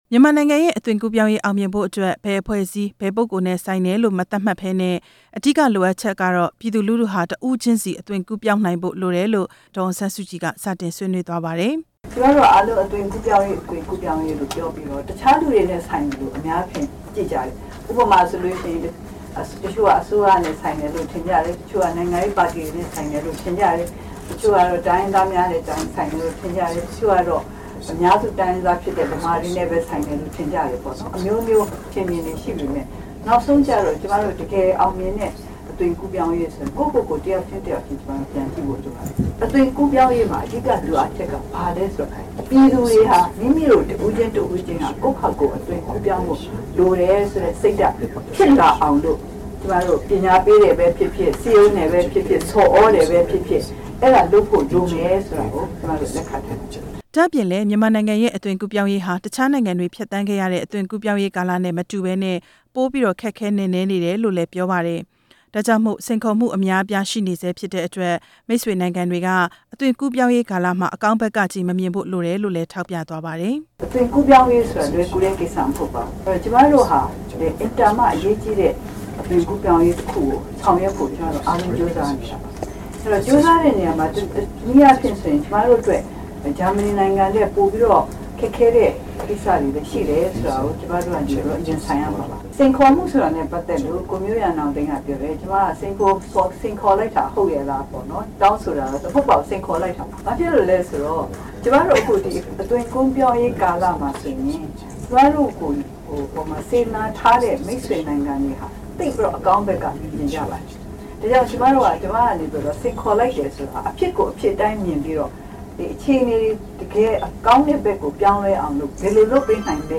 ဒေါ်အောင်ဆန်းစုကြည်ရဲ့ ပြောကြားချက်
မြန်မာနိုင်ငံမှာ တရားဥပဒေ စိုးမိုးမှုရှိမှ ပြည်တွင်းငြိမ်း ချမ်းရေး ခိုင်မာမှုရှိမယ်လို့ NLD ပါတီ ဥက္ကဌ ဒေါ်အောင် ဆန်းစုကြည်က ပြောကြားလိုက်ပါတယ်။ ရန်ကုန်မြို့ ရန်ကင်းမြို့နယ်က မီကာဆာဟိုတယ်မှာ မနေ့က စပြီး နှစ်ရက်ကြာပြုလုပ်နေတဲ့  “မြန်မာနိုင်ငံ အသွင် ကူးပြောင်းရေးကာလ စိန်ခေါ်မှုများ“ ဆိုတဲ့ အလုပ်ရုံဆွေးနွေးပွဲမှာ ဒေါ်အောင်ဆန်းစုကြည်က အခုလို ပြောကြားခဲ့တာပါ။